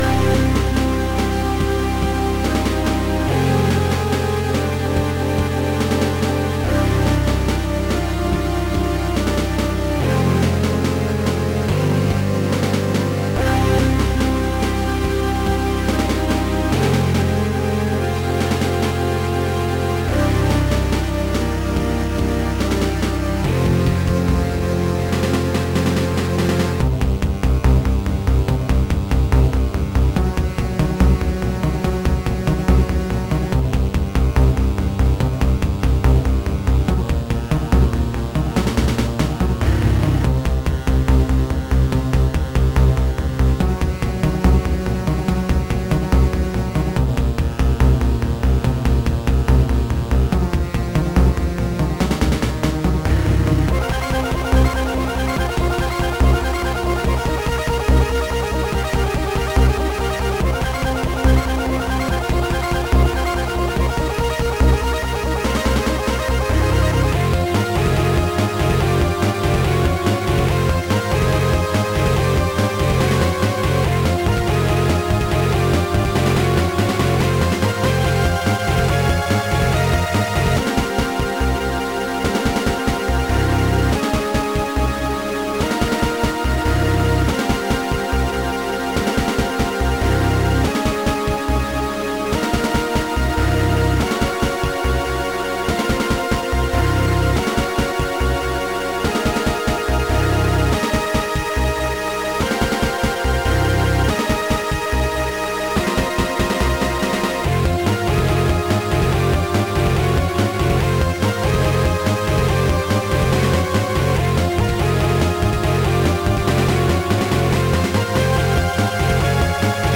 This game features digital titlemusic
Music written with Quartet